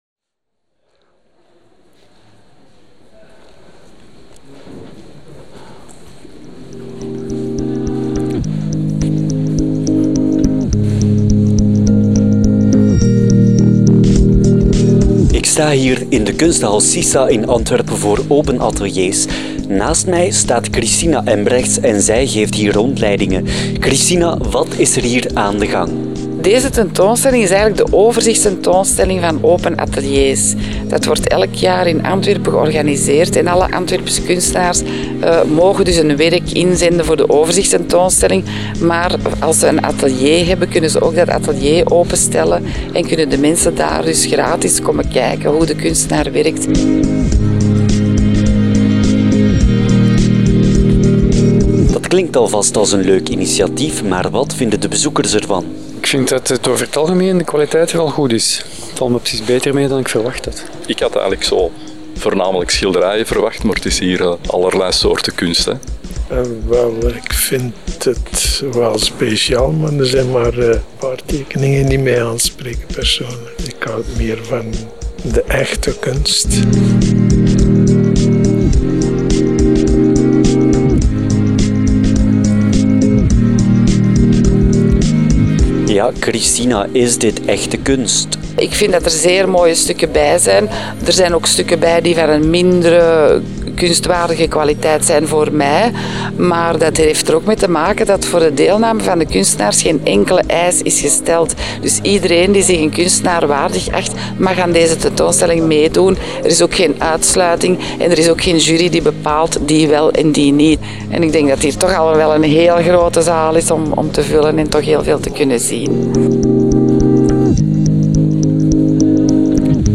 Ook het voorbije weekend was het Open Ateliers.